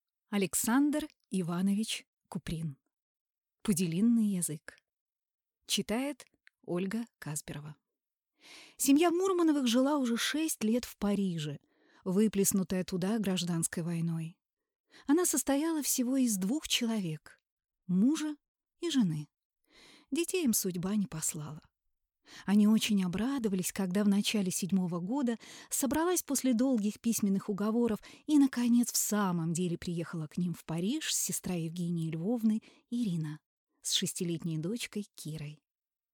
Аудиокнига Пуделиный язык | Библиотека аудиокниг